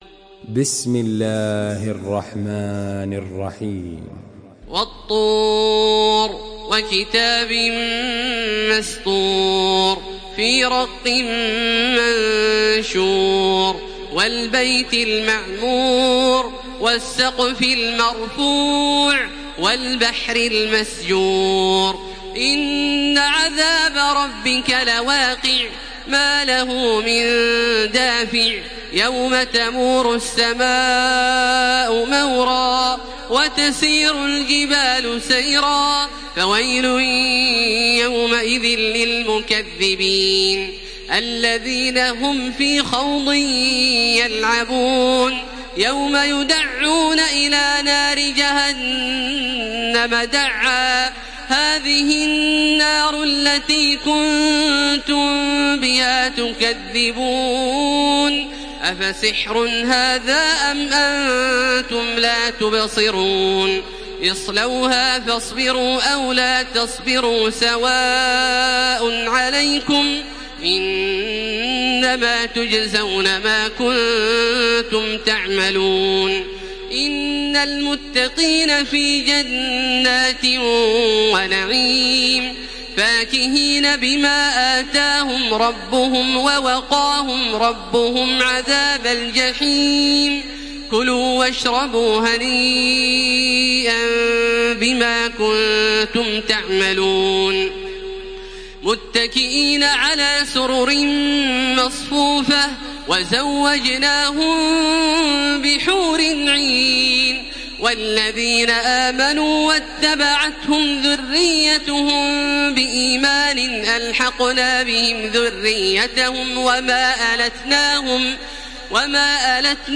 Surah الطور MP3 in the Voice of تراويح الحرم المكي 1433 in حفص Narration
مرتل